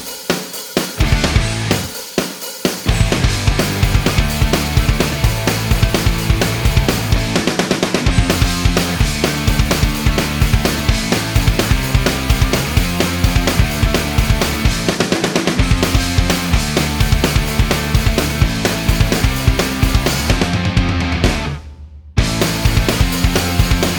no Backing Vocals or TV Clips Rock 3:25 Buy £1.50